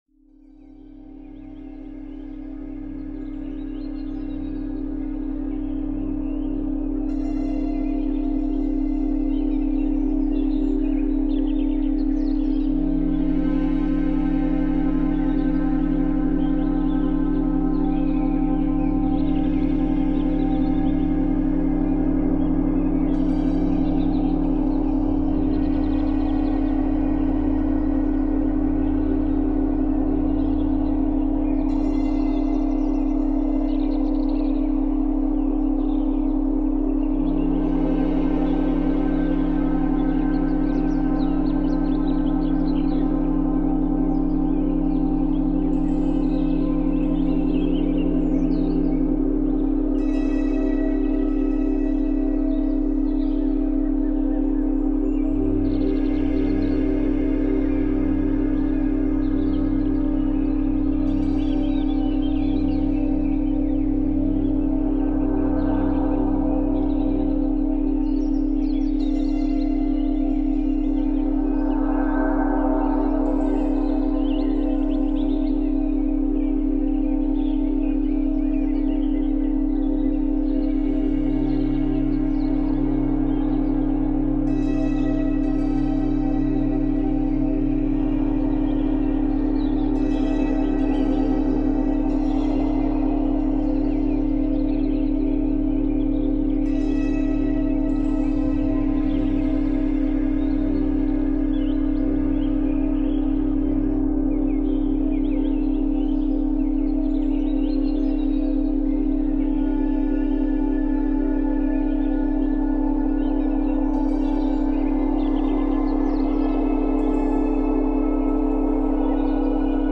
自己接続 – 432 Hz + 3.4 Hz | 瞑想用バイノーラルビート